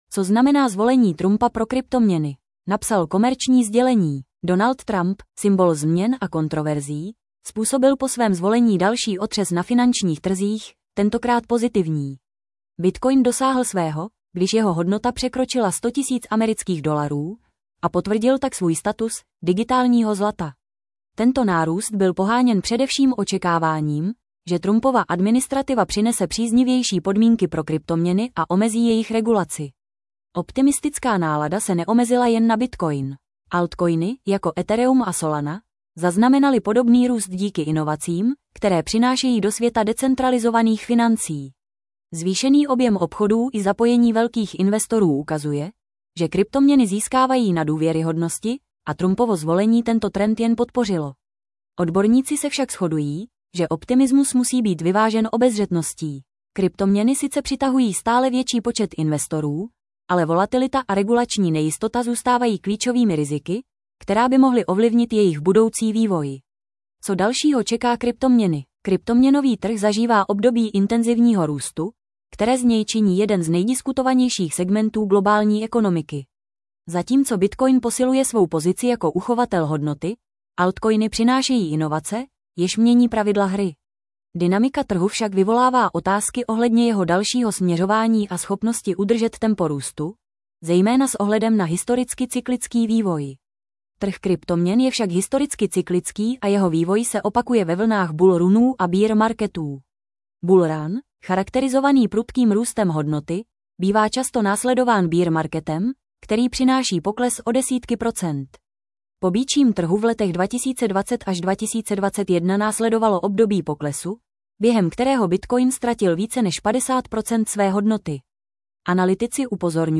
Rychlost přehrávání 0,5 0,75 normální 1,25 1,5 Poslechněte si článek v audio verzi 00:00 / 00:00 Tento článek pro vás načetl robotický hlas.